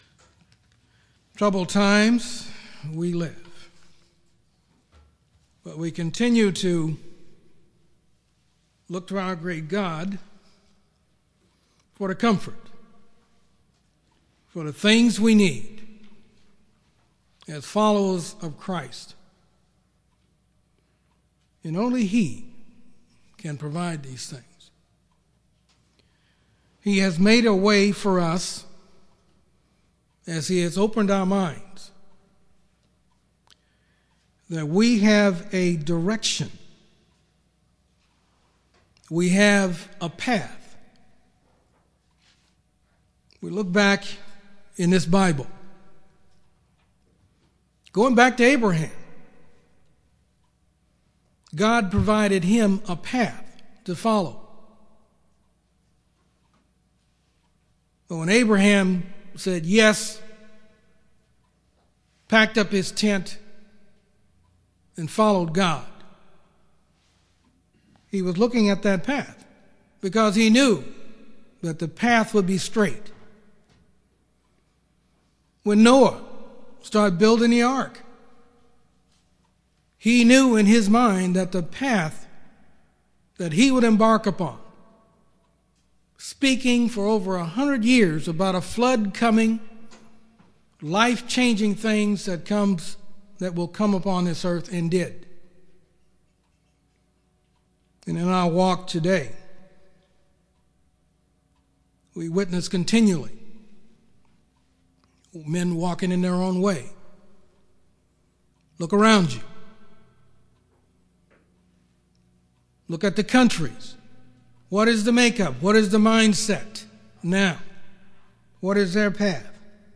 Given in Yuma, AZ